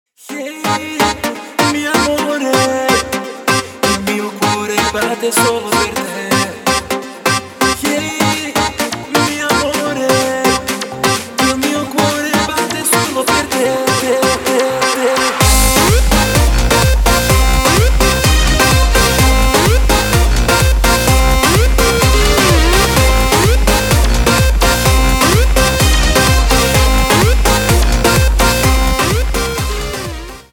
поп
мужской вокал
громкие
dance
EDM
электронная музыка
аккордеон
энергичные